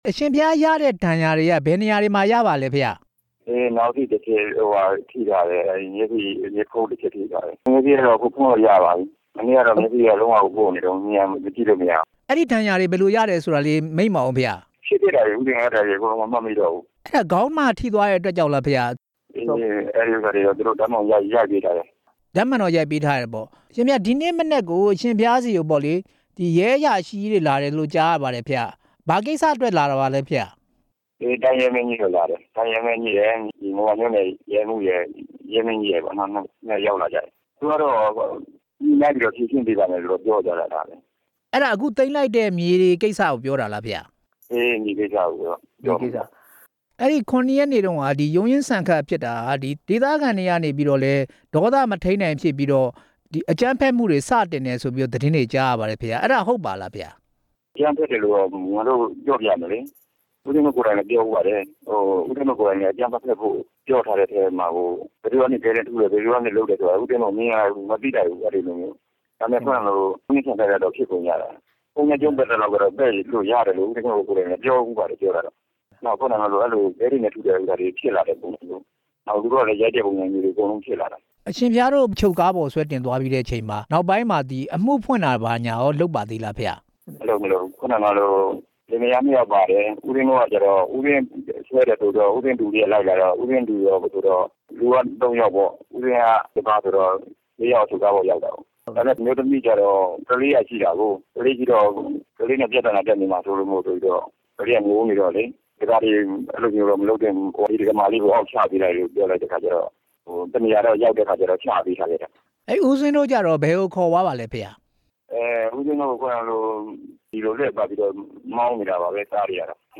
မိန့်ကြားချက်